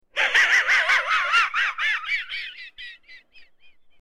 Звуки мужского смеха
Смех со свистящим звуком